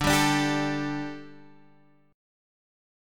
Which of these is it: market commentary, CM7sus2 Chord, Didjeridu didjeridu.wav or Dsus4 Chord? Dsus4 Chord